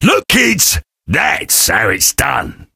sam_kill_vo_03.ogg